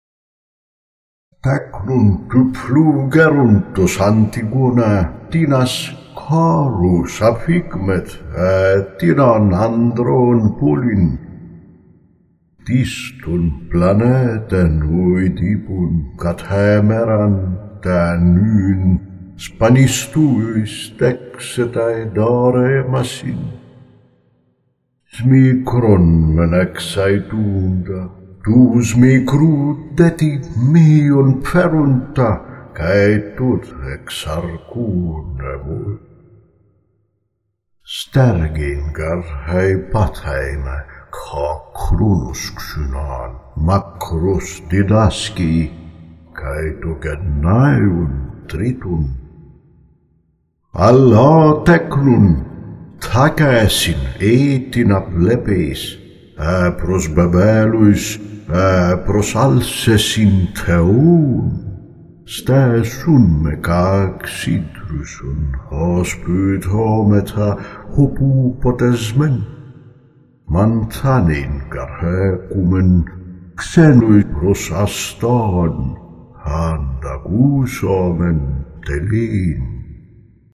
You can follow the original text, recited in reconstructed Ancient Greek pronunciation.